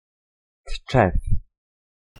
[ṭʧ̑ɛf] (Audio·info)) Poloniako Pomeraniako voivoderriko hiri-udalerri bat da, Tczew barrutian kokatuta. 22,38 km²ko azalera du eta 2017ko datuen arabera 60276 biztanle zituen.
Pl-Tczew.ogg